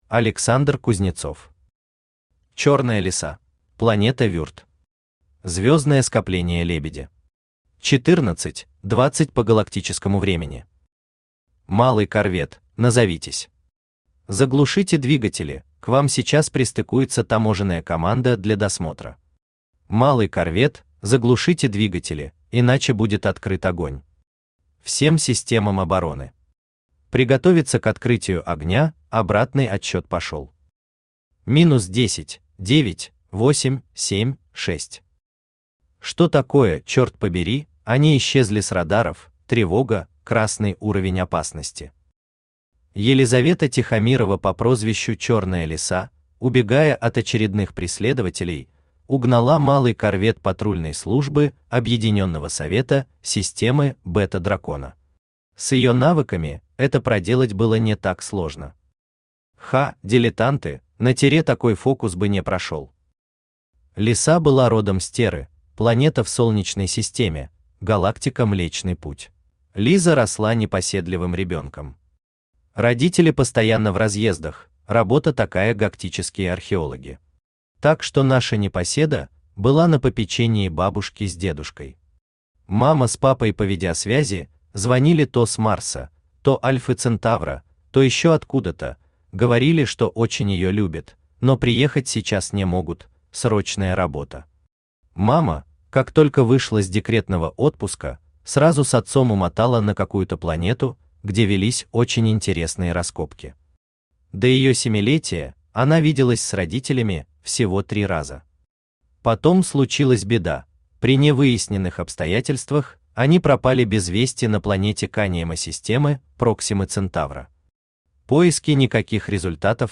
Аудиокнига Черная Лиса | Библиотека аудиокниг
Aудиокнига Черная Лиса Автор Александр Евгеньевич Кузнецов Читает аудиокнигу Авточтец ЛитРес.